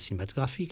Pour cette étude nous avons utilisé 30 heures de corpus radiophonique provenant d'archives INA.
Le style de parole est fluide, spontané et seulement partiellement préparé.
Les spectrogrammes ci-dessous illustrent quelques phénomènes de réduction observables en parole spontanée sur ces syllabes non-accentuées de mots polysyllabiques.